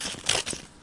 风铃1
描述：想要制作一个漂亮的newage合成垫与宽敞的windchimes。 使用Sony IC录音机录制，使用Fl Studio中的Edison进行清理。
标签： 风铃 放松 NEWAGE 索尼集成电路（IC）记录器
声道立体声